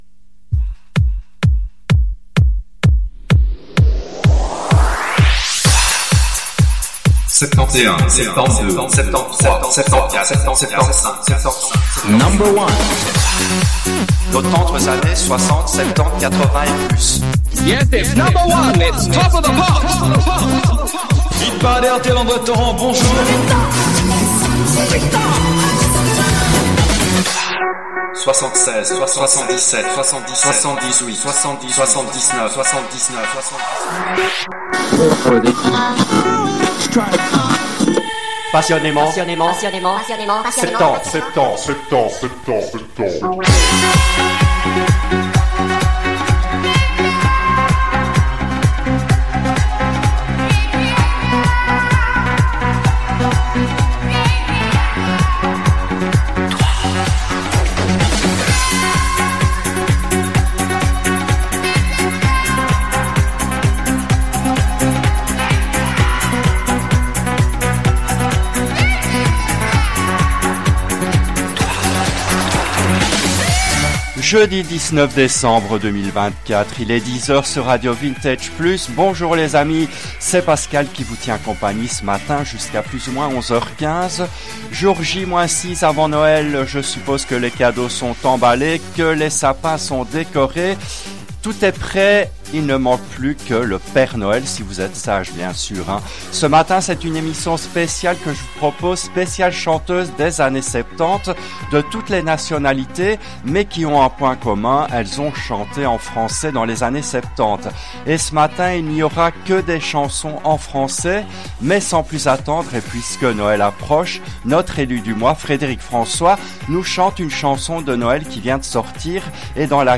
L’émission a été diffusée en direct le jeudi 19 décembre 2024 à 10h depuis les studios belges de RADIO RV+.